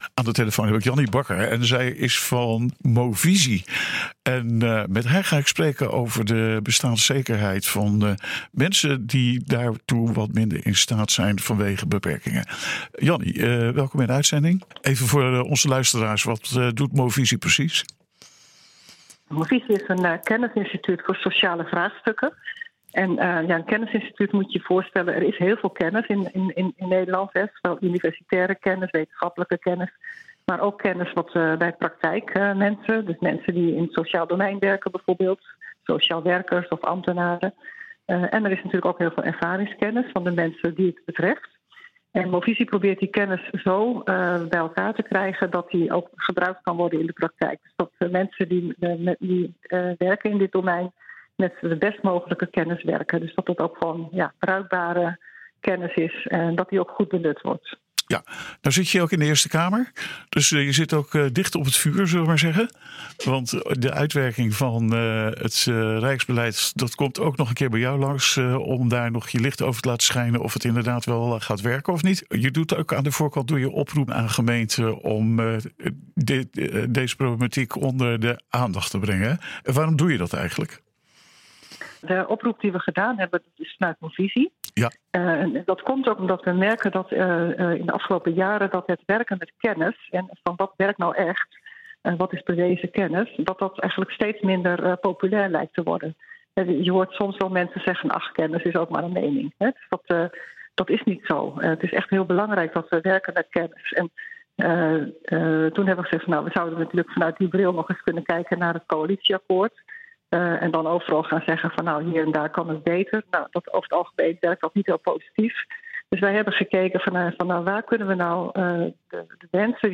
interviewde